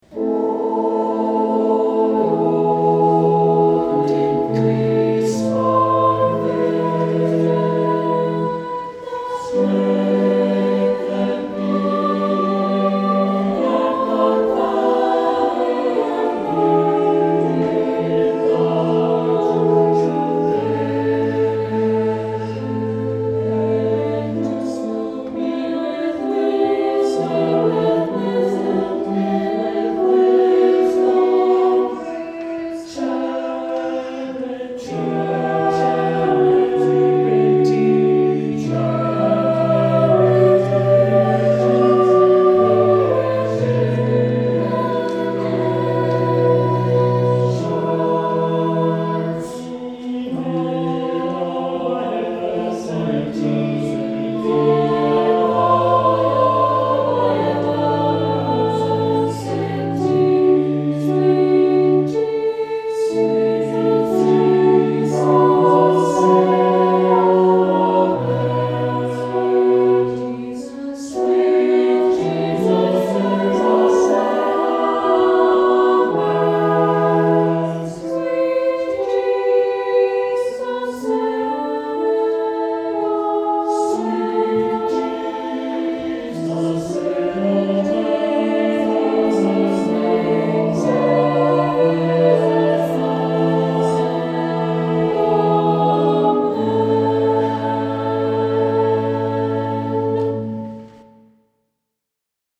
Saint Clement Choir Sang this Song
Anthem